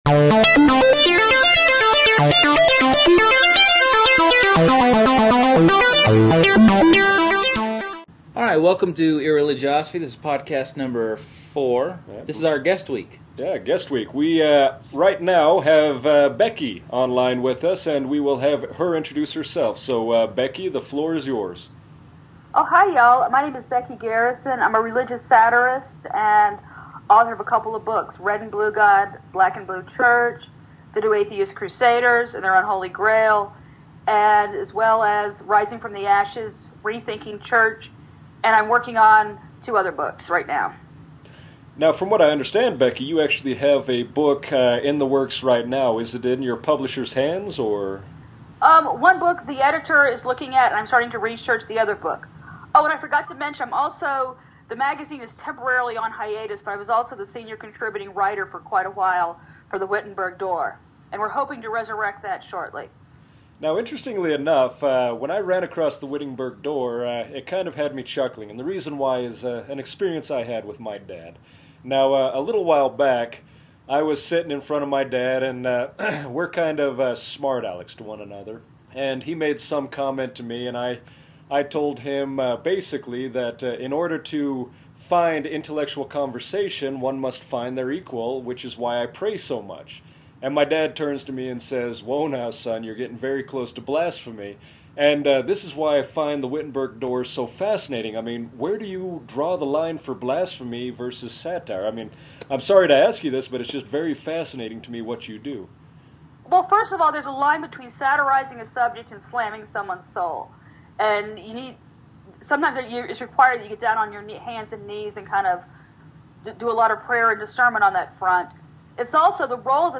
Wherein we interview author and Christian